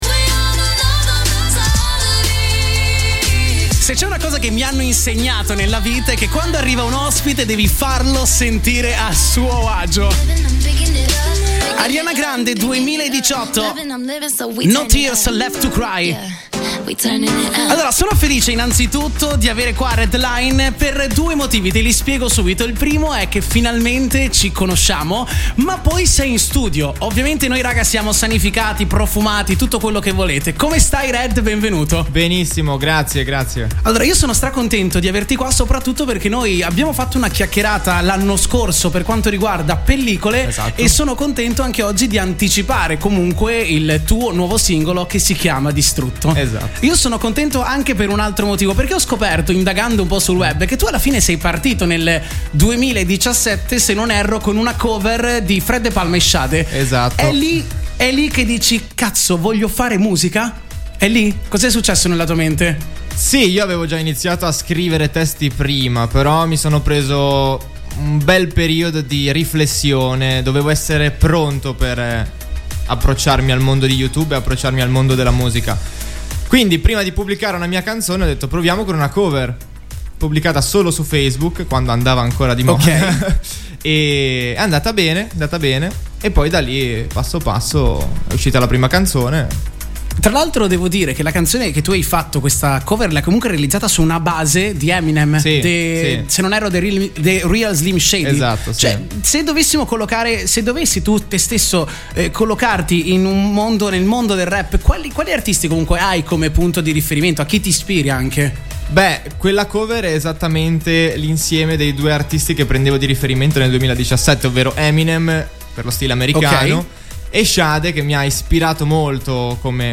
Intervista RedLine